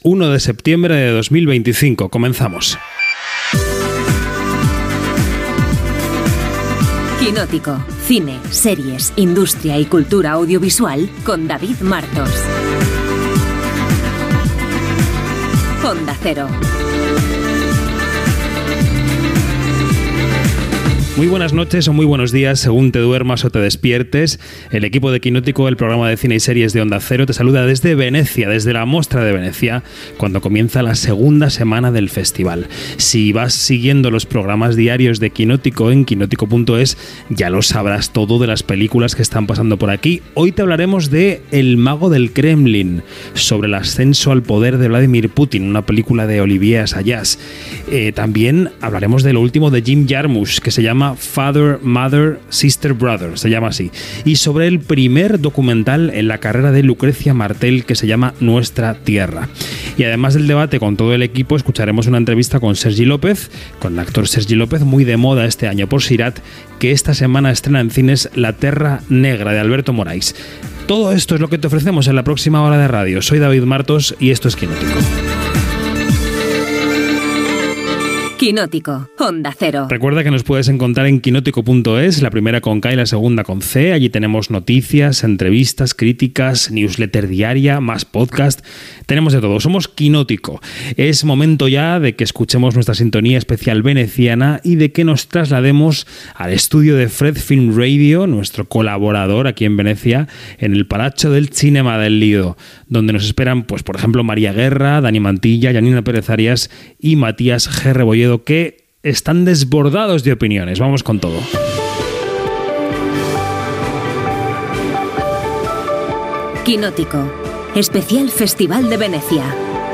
Careta del programa de cinema i sèries. Espai fet des de la Mostra de Venècia de Cinema.